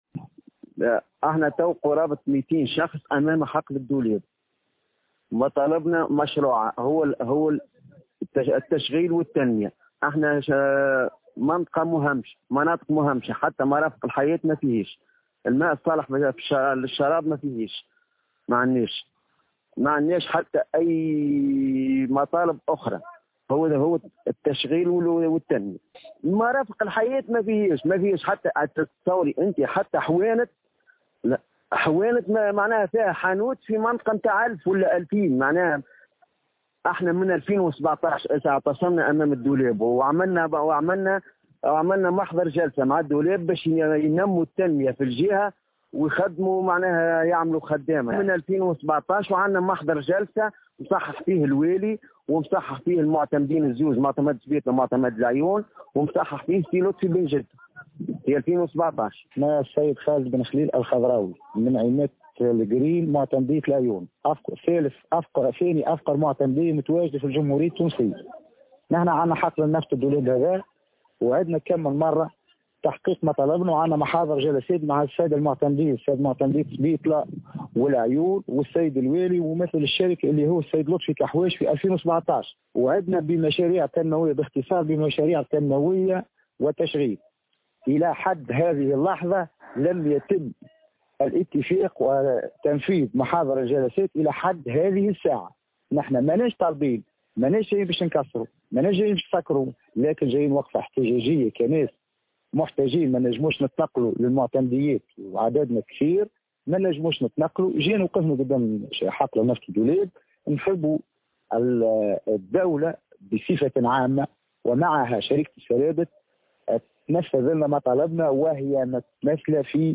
أحد المعتصمين يتحدث للجوهرة أف أم